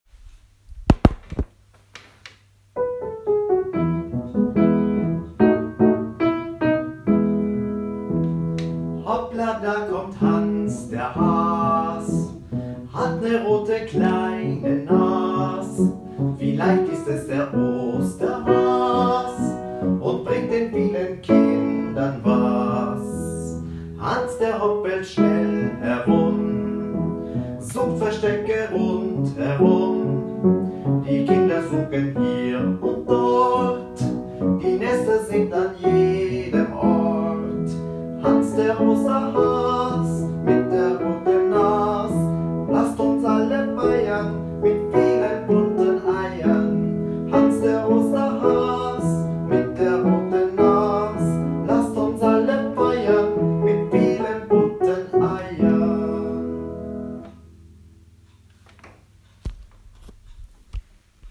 Gattung: Lied Komponist
Besetzung: Chor (1-stimmig)
Ein einstimmiges Osterlied mit Percussion